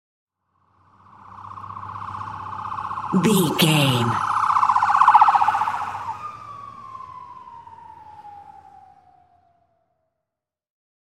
Ambulance Int Drive Engine Stress Large Siren
Sound Effects
urban
chaotic
emergency